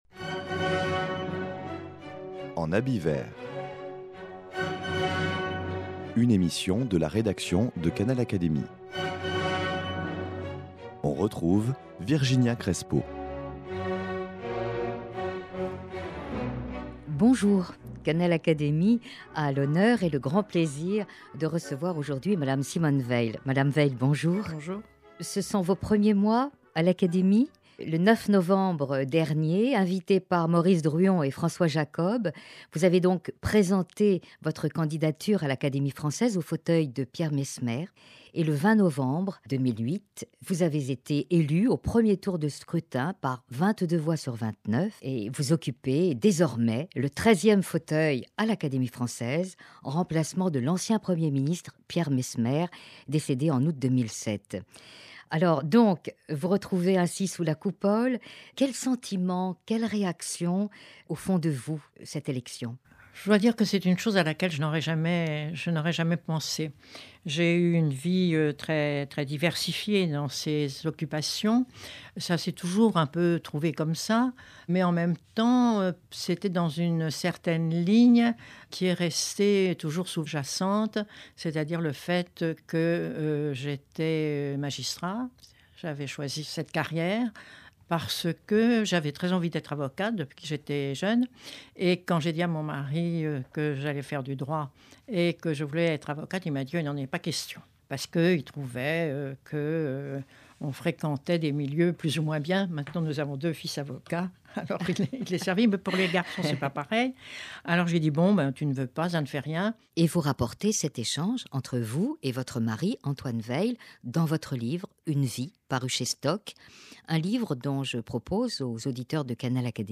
Simone Veil : Conversation autour d’une vie "très diversifiée" 1/4